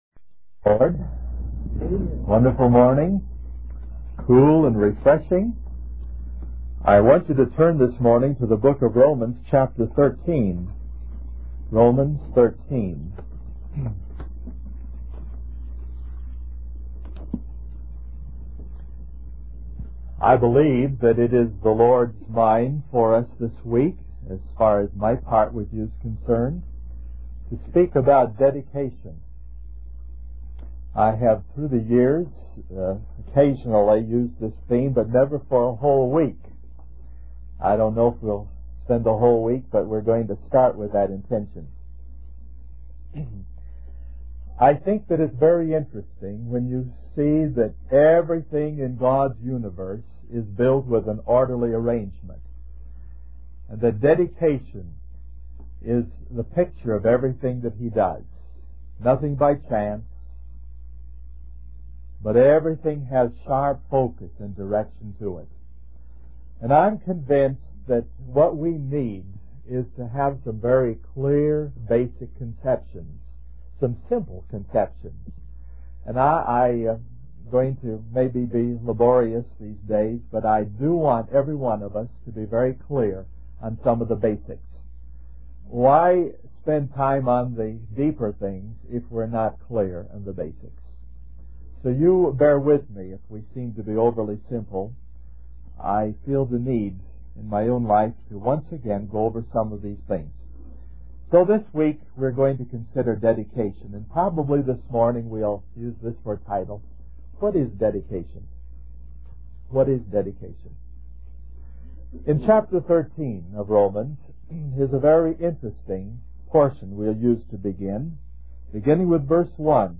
1973 Christian Family Conference Stream or download mp3 Summary In this message